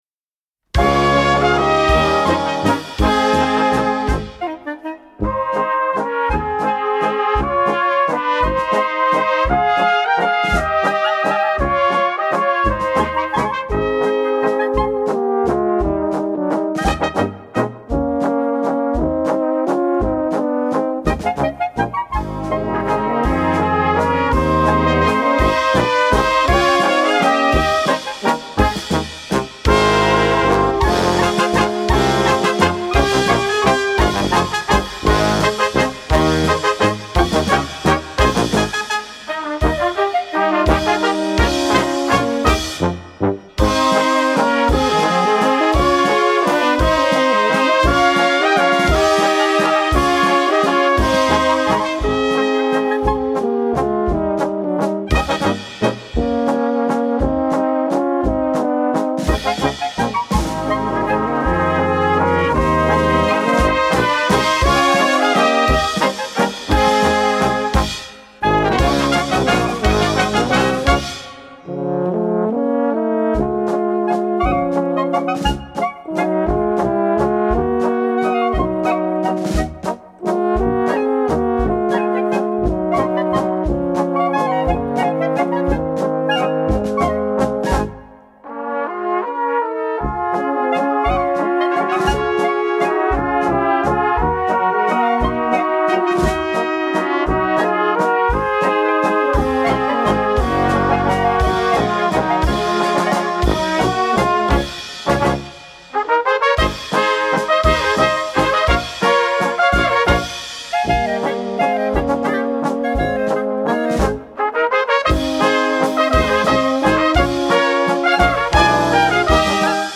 Gattung: Walzer für kleine Besetzung
Klarinette Es
1.-3.Flügelhorn
1.-3.Trompete
Tenorhorn B
1.-3.Posaune C/B
Tuba B
Schlagzeug